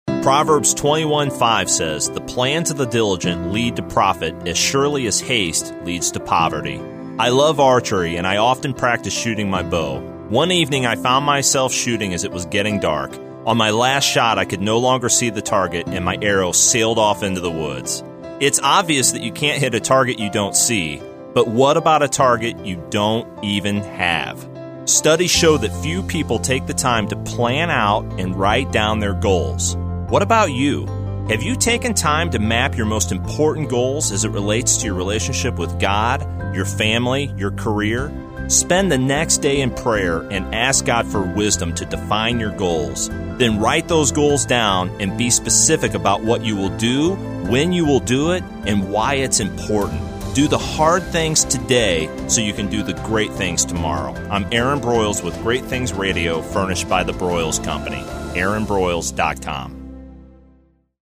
I’m excited to introduce Great Things Radio (1 min motivational messages) airing now on Bott Radio Network on 91.5 FM in St. Louis at approximately 5:35 p.m. CST (top of the second break in the Bible Answer Man Hank Hanegraaff broadcast).